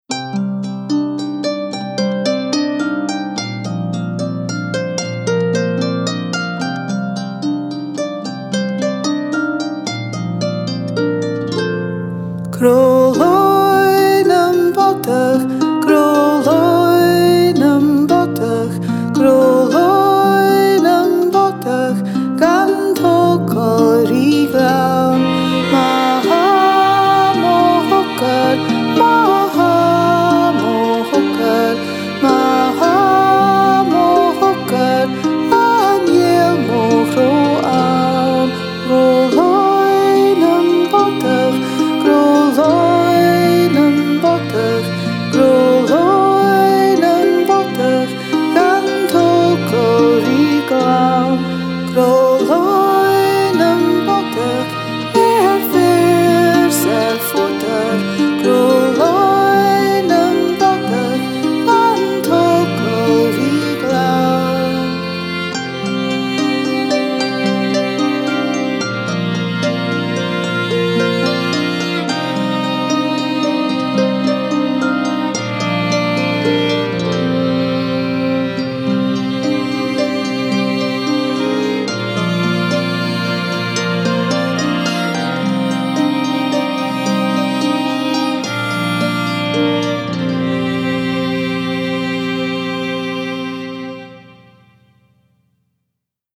Gaelic Music Download Crodh-Laoigh nam Bodach MP3